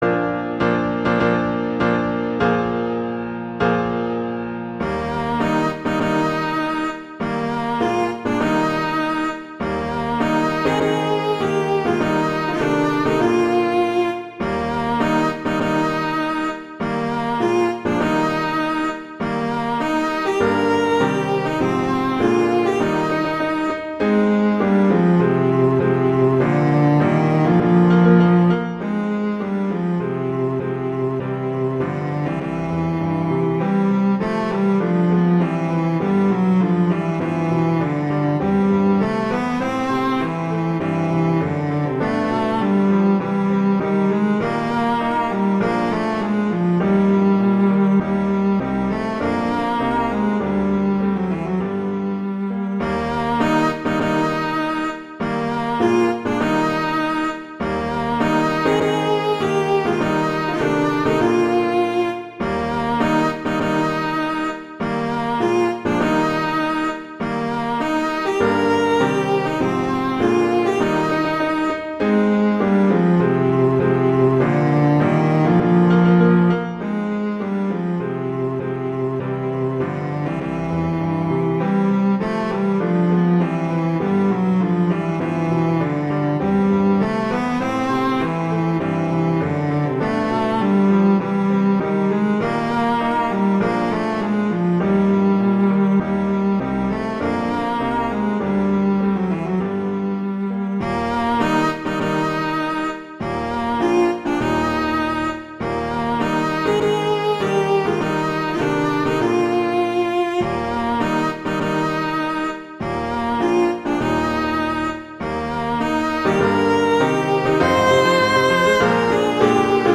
arrangements for cello and piano (organ)
wedding, traditional, classical, festival, love, french